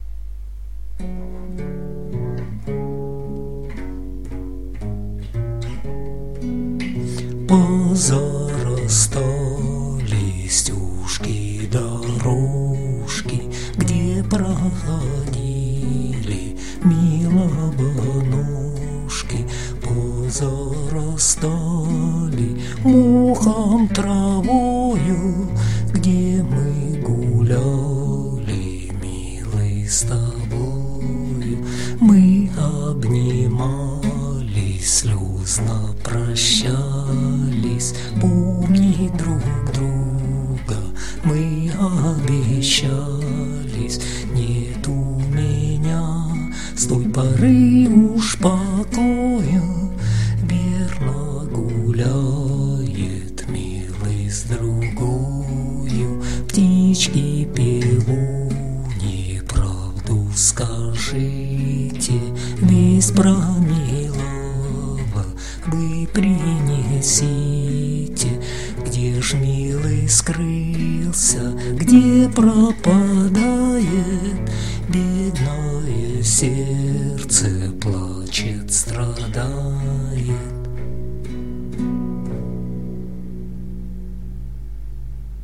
../icons/polushko.jpg   Русская народная песня